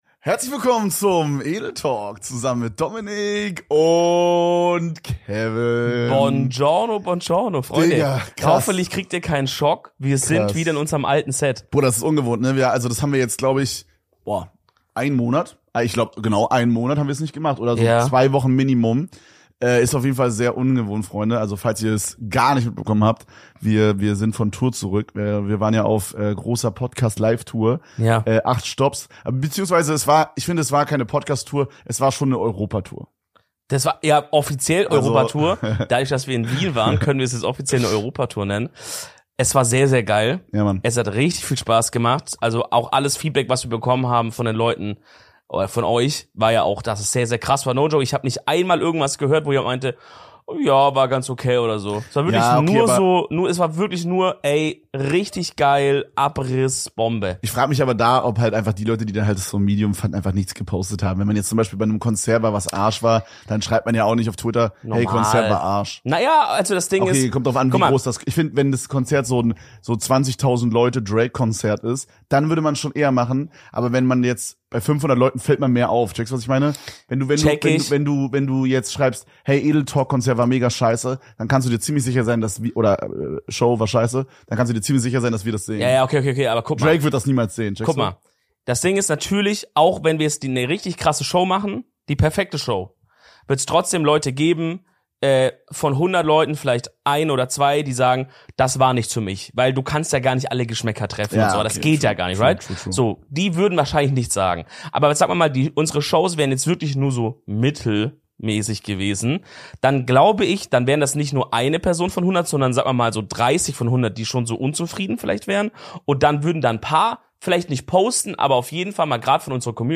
Die Jungs sind wieder zurück von der Tour im guten alten Studio und reden neben zukünftigen Wunschgästen im Podcast besonders über ihre Tour-Highlights.